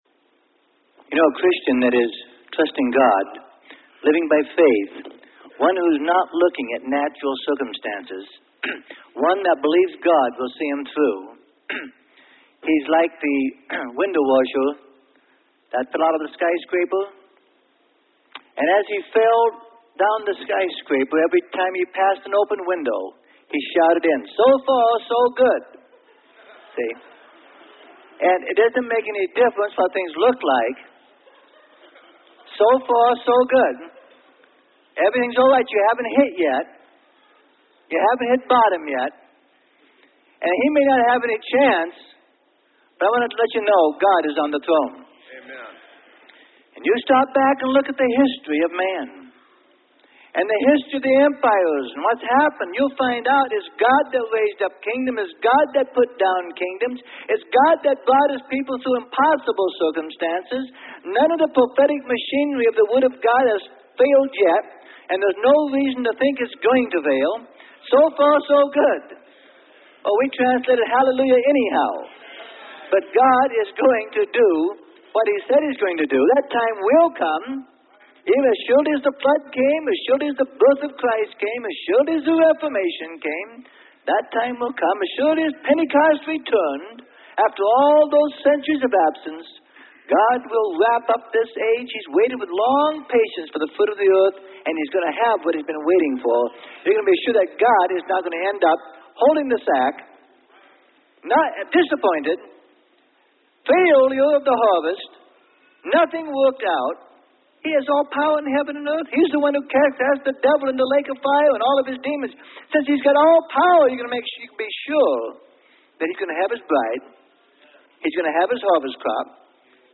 Sermon: God'S Anointed One Is Born - Freely Given Online Library